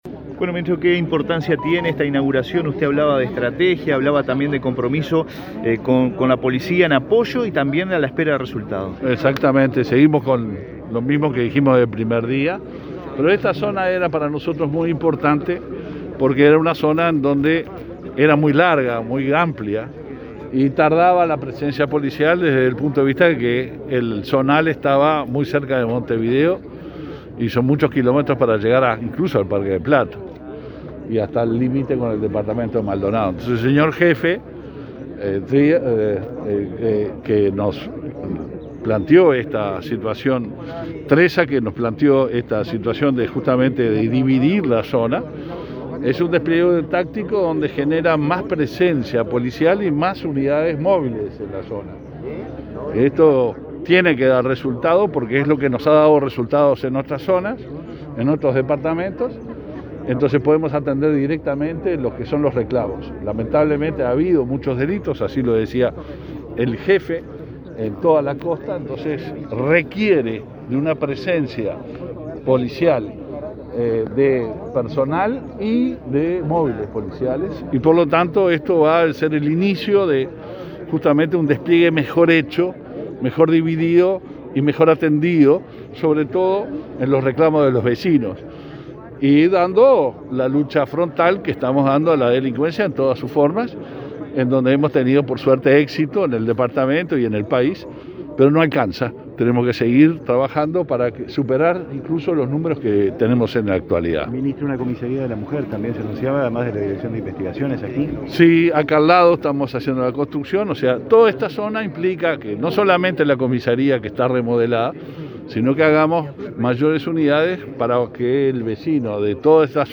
Declaraciones a la prensa del ministro del Interior, Luis Alberto Heber